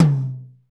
Index of /90_sSampleCDs/Northstar - Drumscapes Roland/KIT_R&B Kits/KIT_R&B Dry Kitx
TOM R B H0WR.wav